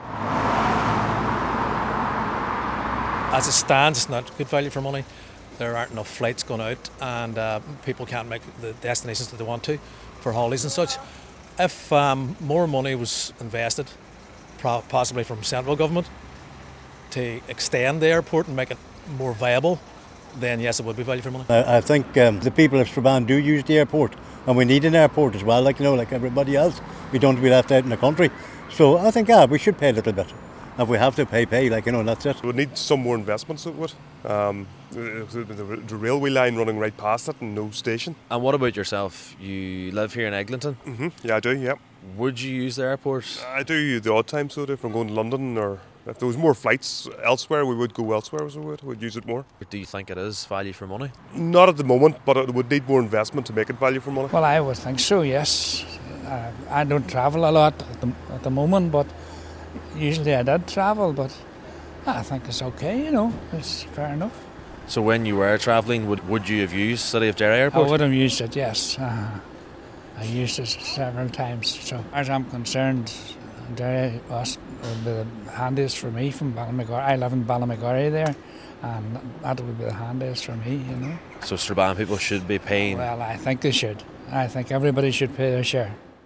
asking people in Strabane and Eglinton if they think the airport is value for money...